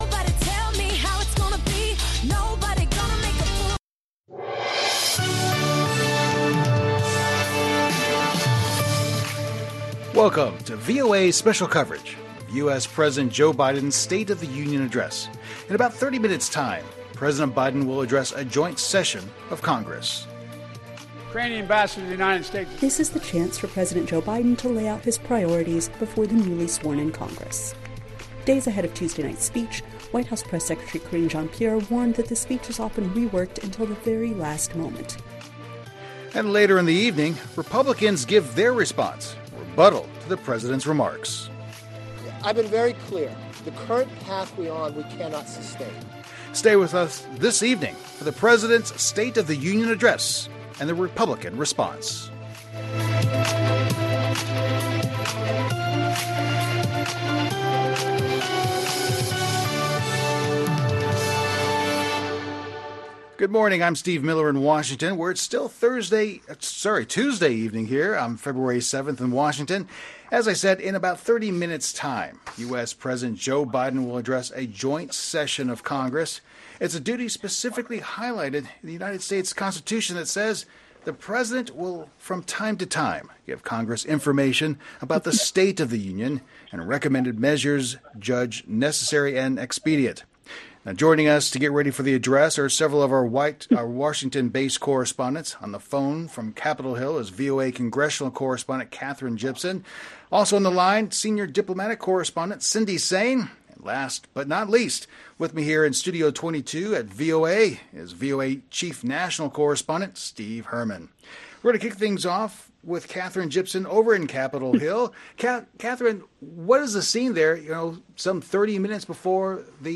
Coverage of President Biden's State of the Union address to Congress.